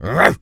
pgs/Assets/Audio/Animal_Impersonations/dog_large_bark_06.wav at master
dog_large_bark_06.wav